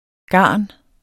Udtale [ ˈgɑˀn ]